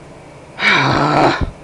Frustrated Sound Effect
frustrated-2.mp3